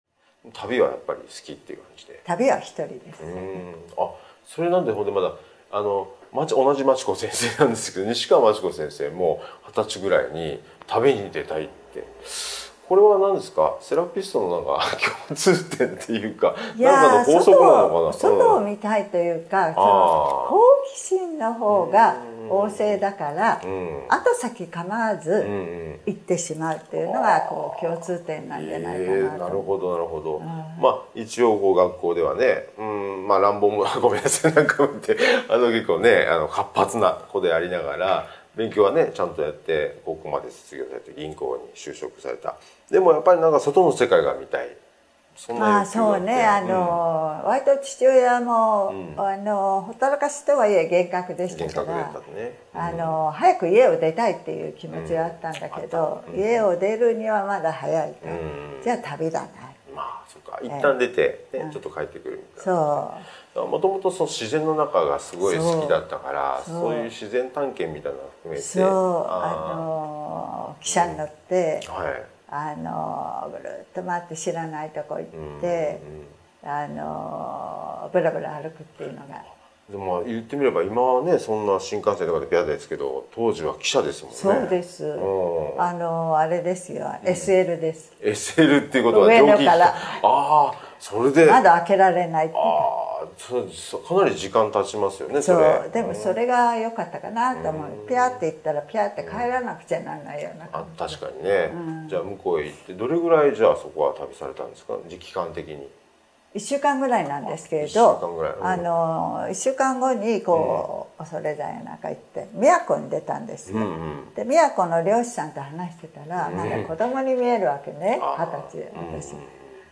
この第9回 6つのパートのうち１パート分の対談内容を、特別に無料で音声試聴いただけます！